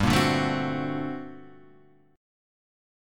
G Minor Major 11th